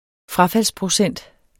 Udtale [ ˈfʁɑˌfals- ]